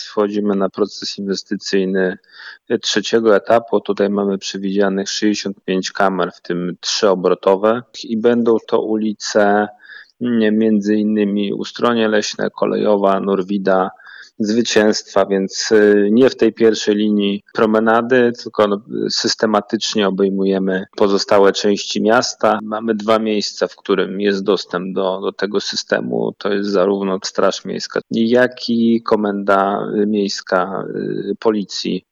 Informuje Mateusz Bobek, burmistrz Międzyzdrojów